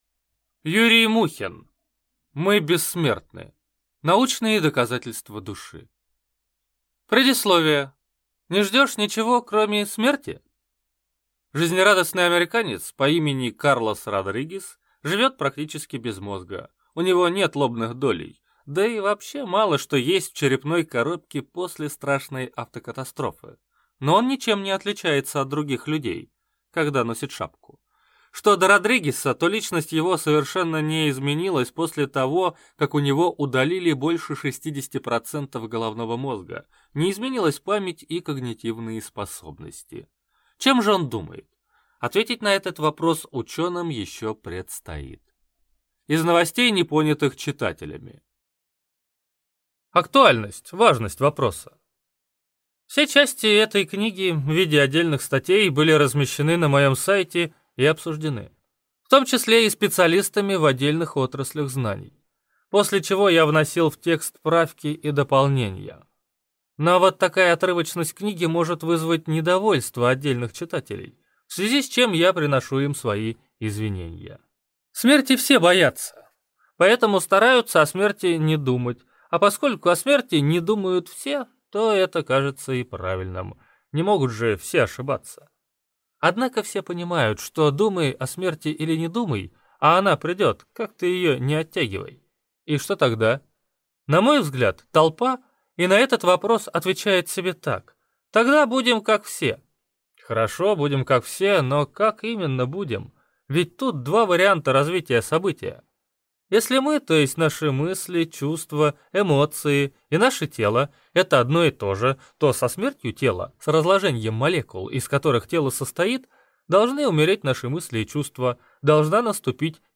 Аудиокнига Мы бессмертны! Научные доказательства Души | Библиотека аудиокниг
Прослушать и бесплатно скачать фрагмент аудиокниги